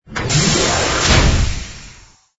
cargo_jettison.wav